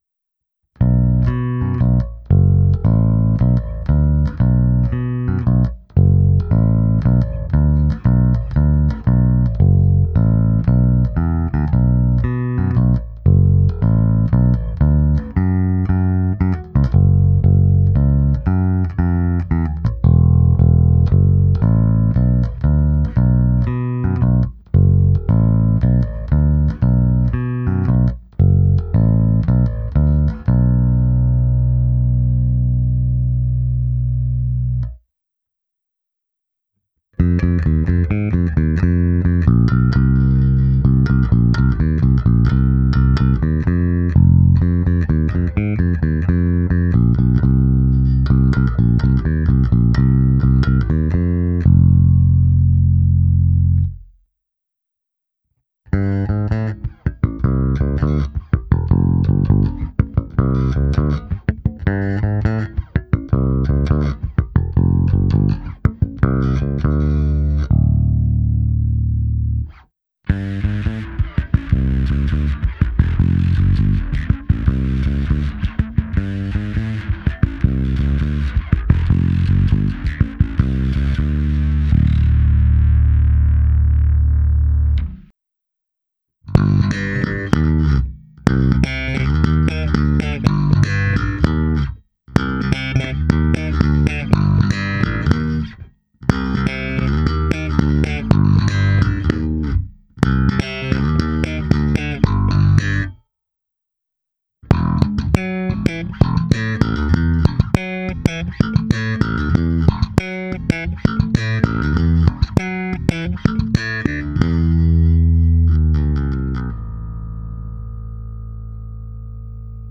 Nahrávka se simulací aparátu, kde bylo použito i zkreslení a hra slapem. V první části je krkový snímač, pak oba snímače, kobylkový snímač bez a se zkreslením, slap na oba snímače a nakonec hra na struně H na oba snímače.